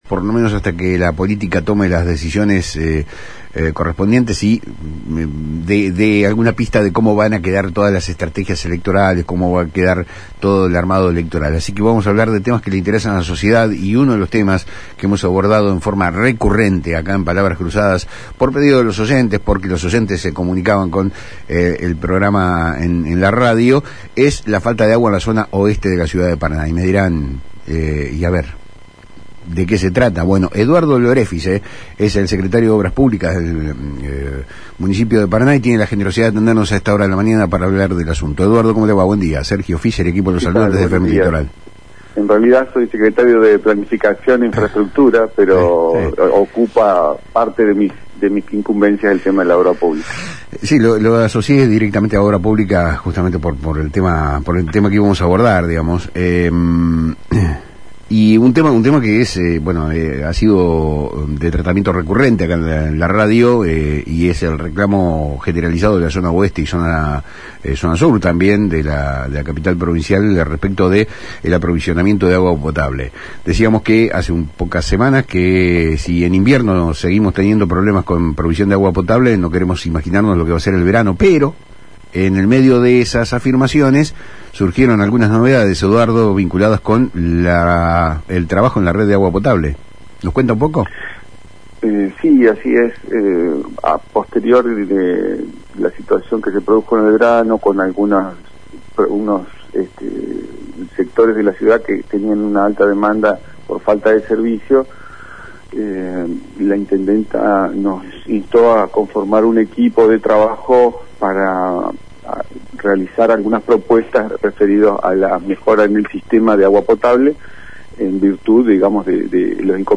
El Secretario de Planificación e Infraestructura de la Municipalidad de Paraná, Eduardo Lorefice, se refirió en Palabras Cruzadas por FM Litoral a los problemas actuales con el suministro de agua potable en la ciudad, especialmente en las zonas oeste y sur.